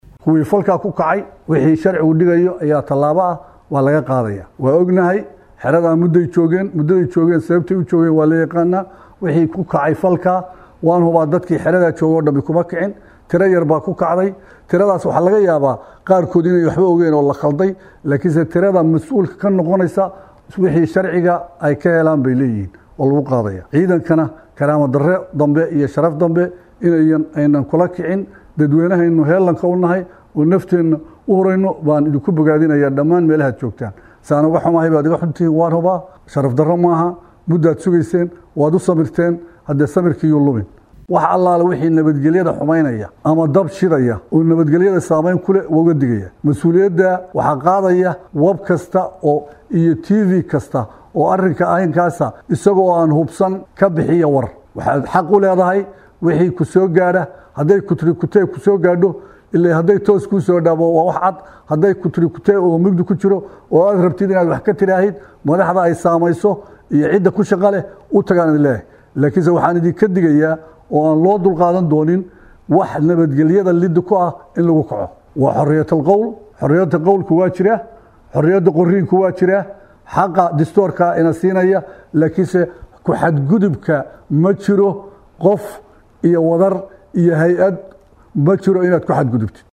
Taliyaha ayaa si kulul uga hadlay gadoodkaasi, waxaana uu tilmaamay in ciidamo aan badneyn ay xiisadaasi kiciyeen, iminkana ay sharciga ay wajihi doonaan.
Taliyaha-Xooga-Dalka.mp3